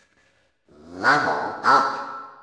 level-up.wav